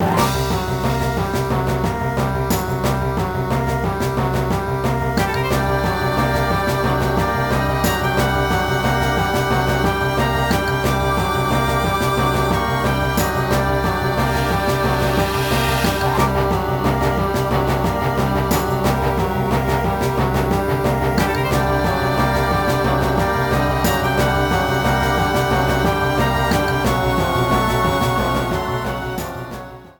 Clipped to 30 seconds and added fade-out.